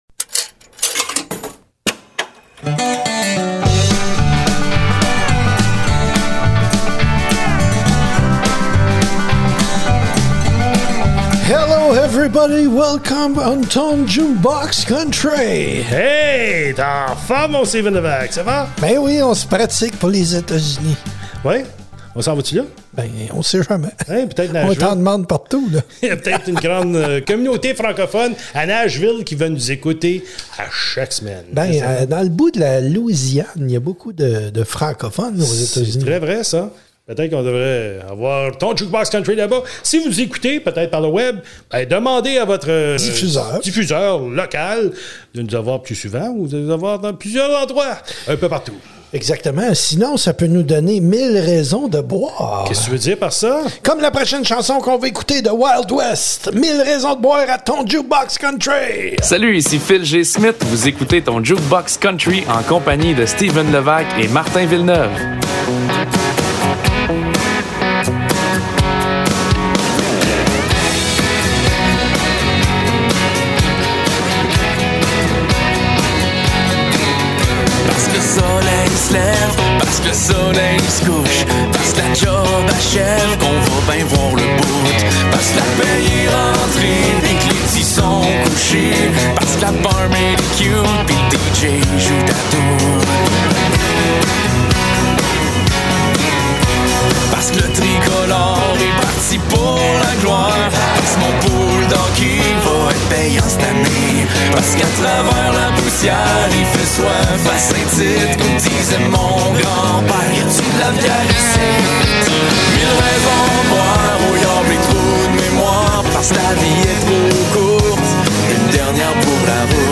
Des entrevues avec les artistes du monde du Country francophone à écouter sur les ondes de la CEFR. Format: musique Country francophone, entrevues et humour.